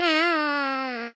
yoshi_yawn.ogg